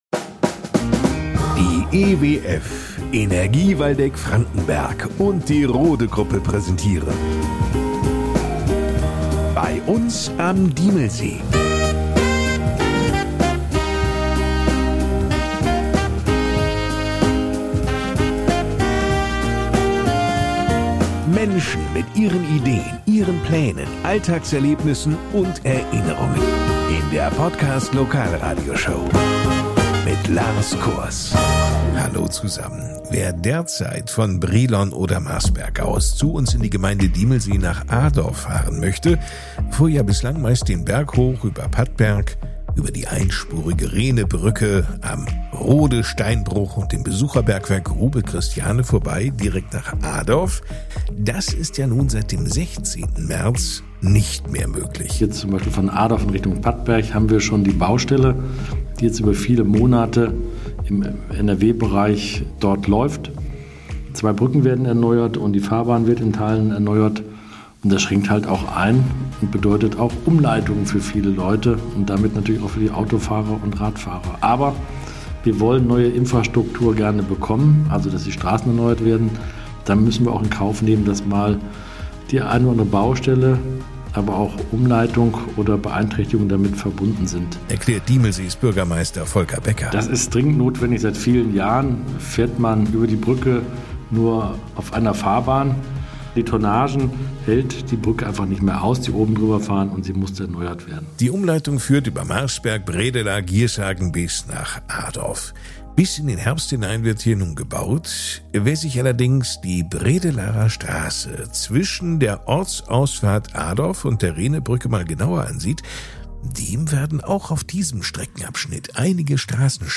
Die Podcast-Lokalradioshow für die schönste Gemeinde im Upland